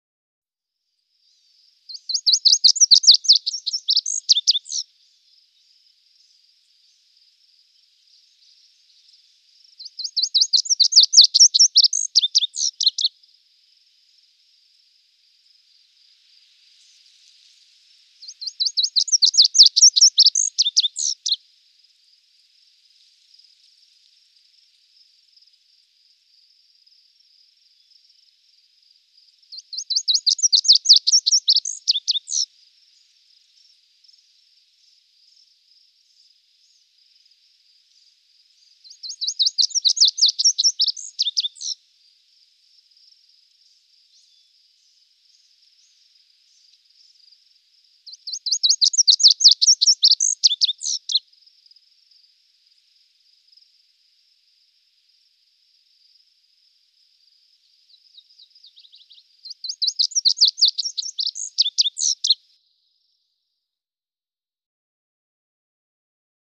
Lazuli-bunting Tweets. Hi-pitched Tweets With Slight Birds And Insects Ambience. Close Perspective.